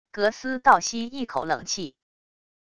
格斯倒吸一口冷气wav音频